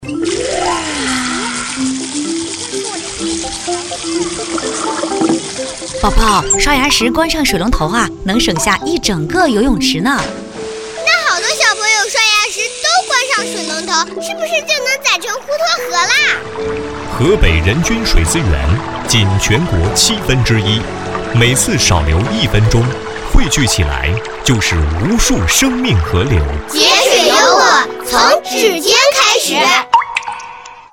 作品以母子刷牙对话建立情感共鸣